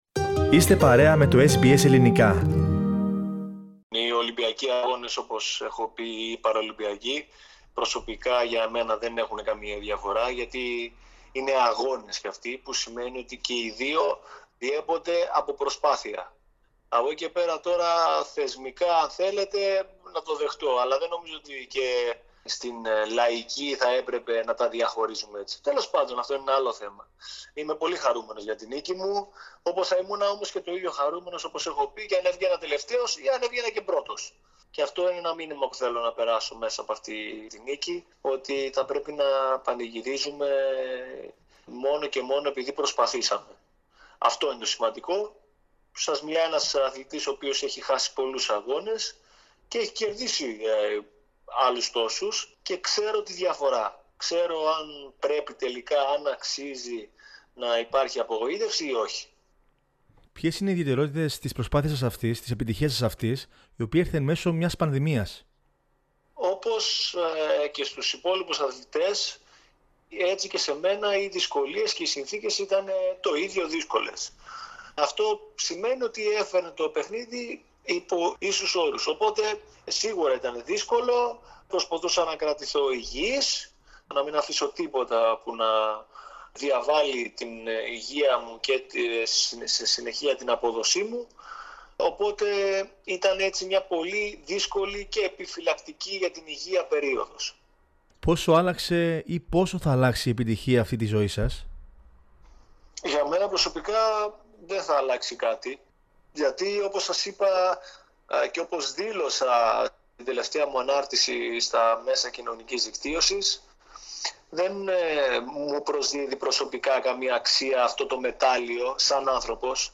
Κύρια Σημεία Κατέκτησε το χάλκινο μετάλλιο στην κολύμβηση Στόχος του να εμπνεύσει άλλους ανθρώπους Ποιό είναι το μήνυμα που στέλνει στην πολιτεία Μιλώντας στο Ελληνικό Πρόγραμμα της Ραδιοφωνίας SBS, μετά την κατάκτηση του χάλκινου μεταλλίου στο Τόκιο, ο κ. Τσαπατάκης ξεκαθάρισε πως δεν είναι ένα άτομο με αναπηρία, αλλά ένας άνθρωπος που κινείται με διαφορετικό τρόπο.